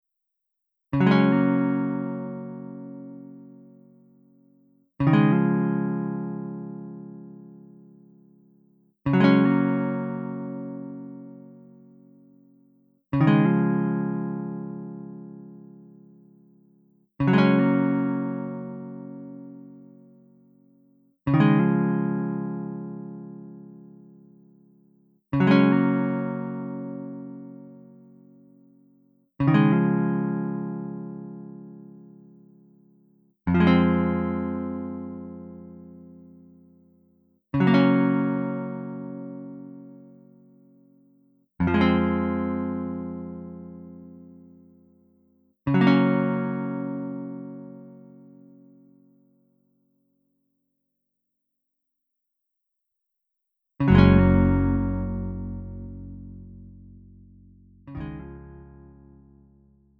음정 -1키 4:31
장르 구분 Lite MR